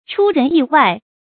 出人意外 注音： ㄔㄨ ㄖㄣˊ ㄧˋ ㄨㄞˋ 讀音讀法： 意思解釋： 超出人們的意料 出處典故： 清 吳趼人《二十年目睹之怪現狀》第九回：「所以天下事往往有 出人意外 的。」